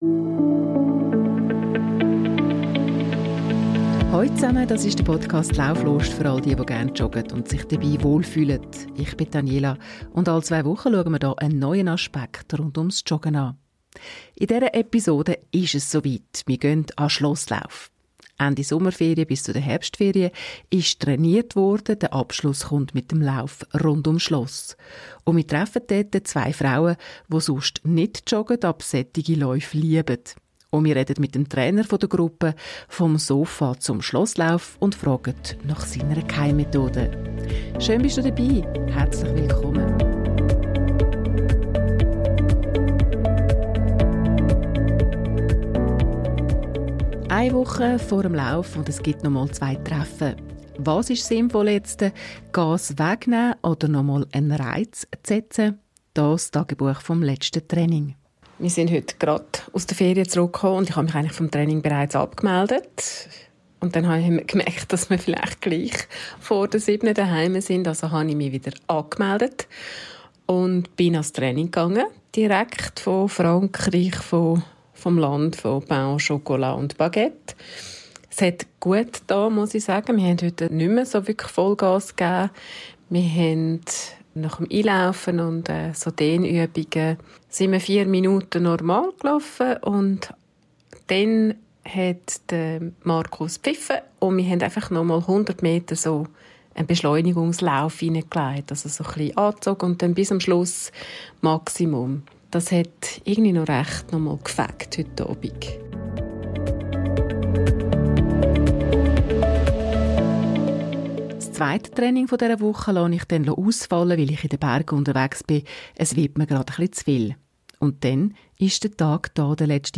Beschreibung vor 1 Jahr Vom Sofa zum Schlosslauf – Der Schlosslauf ist mehr als nur ein Rennen: Er motiviert Menschen, die ersten Schritte zu machen und dranzubleiben. In dieser Folge hören wir, wie zwei Teilnehmerinnen das Event erleben und was sie daran begeistert.